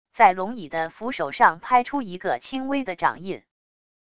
在龙椅的扶手上拍出一个轻微的掌印wav音频